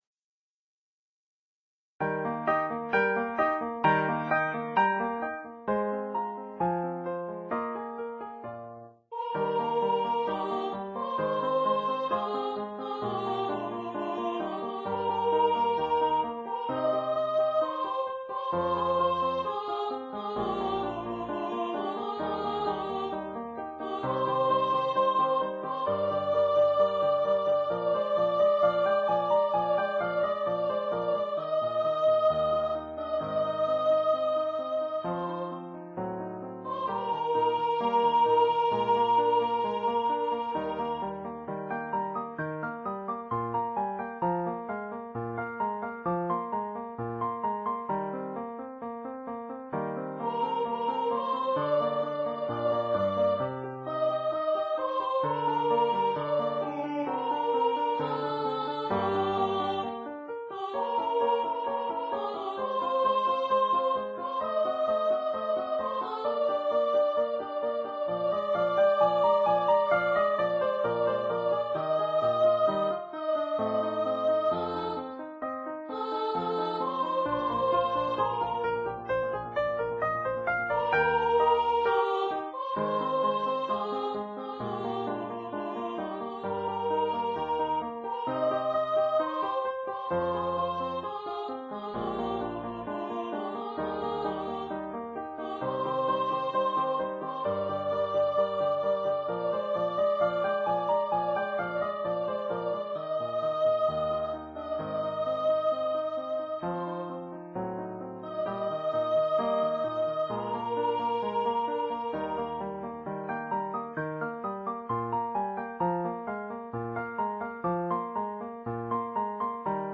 Ernest Reyer, Les Larmes, romance, poésie de Camille du Locle, extrait de Maître Wolfram, pour voix de mezzo-soprano ou de baryton